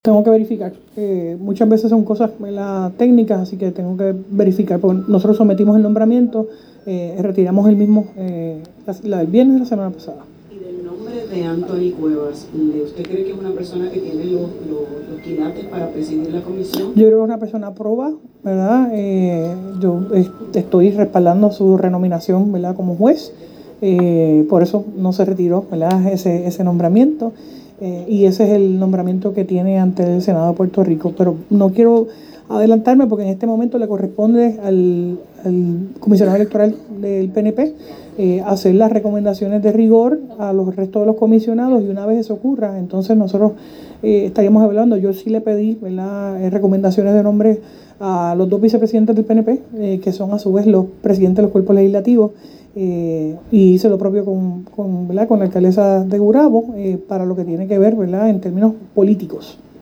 En conferencia de prensa hoy, lunes, la gobernadora Jenniffer González Colón no dio razones sobre por qué retiró el nombramiento de Francisco Rosado Colomer como juez del Tribunal de Primera Instancia.